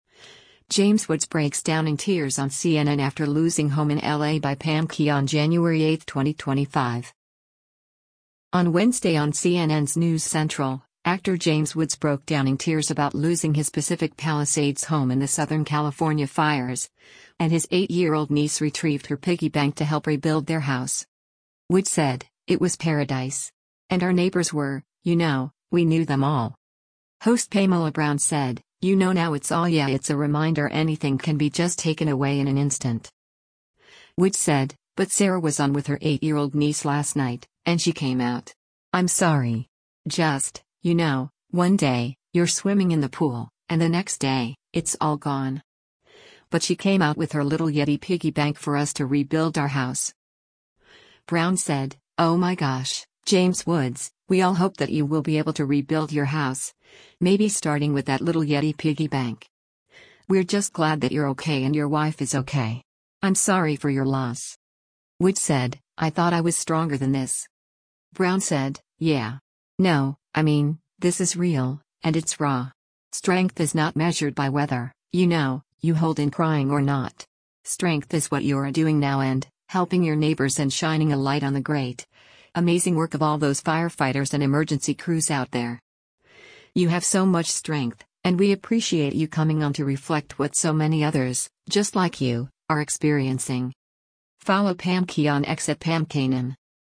James Woods Breaks Down in Tears on CNN After Losing Home in L.A.
On Wednesday on CNN’s “News Central,” actor James Woods broke down in tears about losing his Pacific Palisades home in the Southern California fires, and his 8-year-old niece retrieved her piggy bank to help rebuild their house.